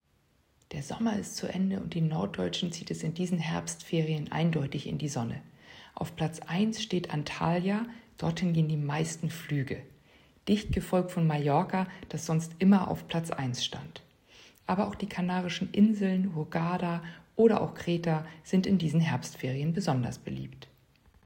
Audio-Statements
O-Ton